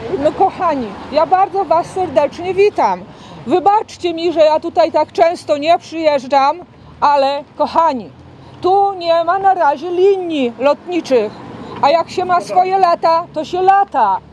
W piątek (17.08) przy okazji wizyty polonijnej młodzieży z Wielkiej Brytanii w wigierskim klasztorze, Anna Maria Anders udzieliła krótkiego wywiadu Radiu 5, w którym odniosła się do stawianych jej zarzutów.